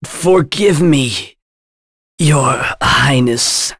Roman-Vox_Dead.wav